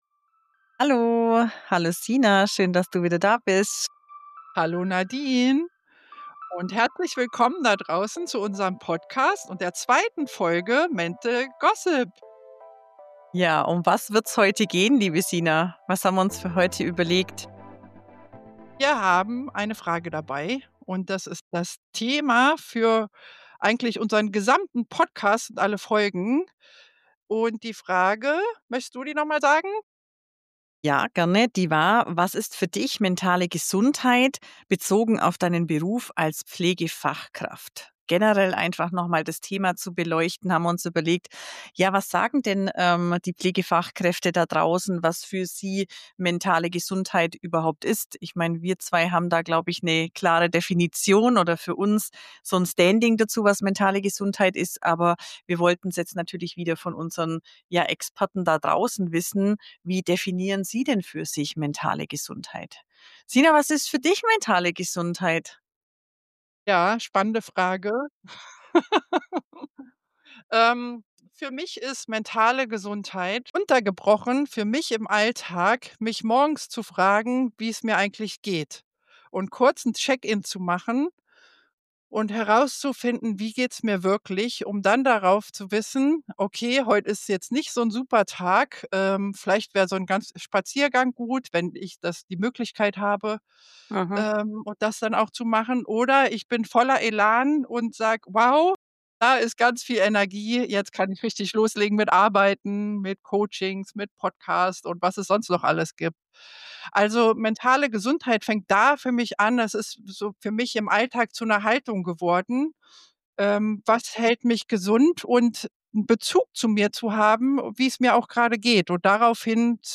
Dazu teilen wir O-Töne von Pflegekräften, die ihre Erfahrungen und Strategien zur Förderung ihrer mentalen Gesundheit schildern. Die Episode schließt mit praktischen Tipps und einem Aufruf zur Verantwortung für die eigene mentale Gesundheit.